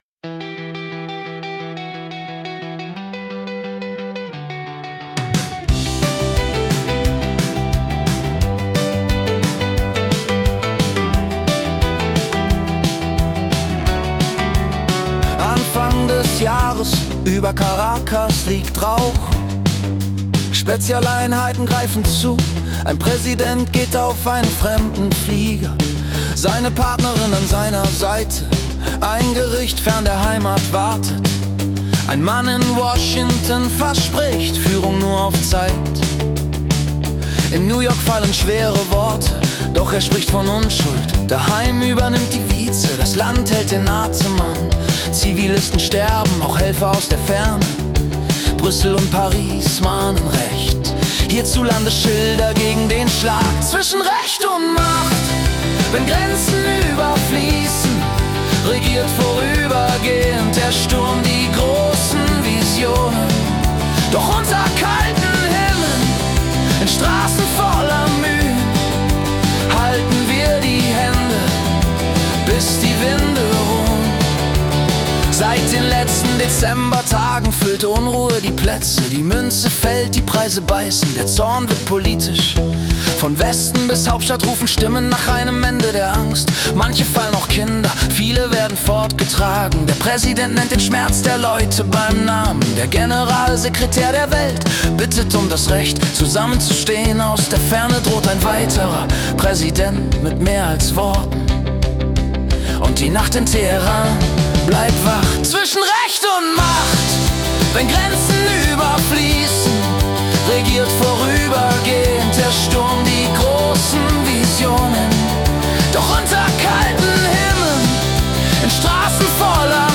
Die Nachrichten vom 6. Januar 2026 als Singer-Songwriter-Song interpretiert.